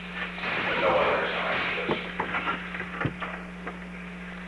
Secret White House Tapes
Conversation No. 532-5
Location: Oval Office